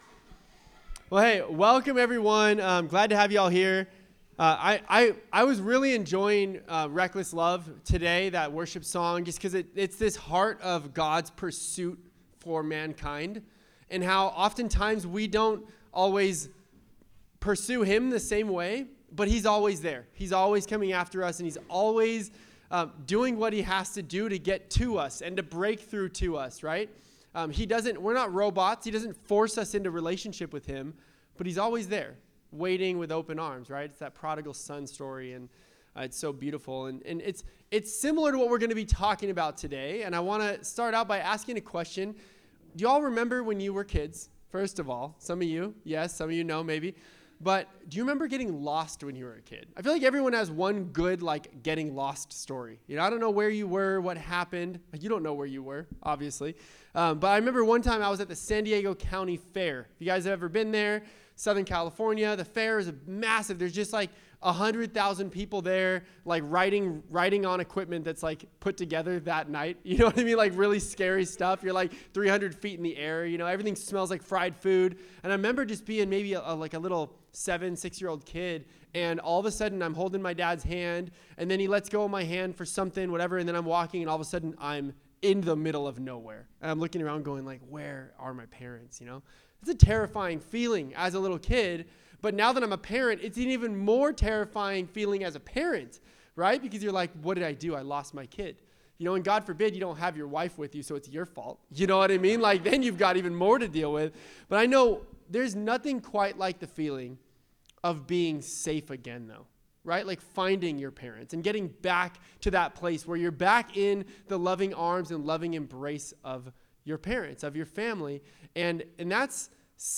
Sermons | Revive Church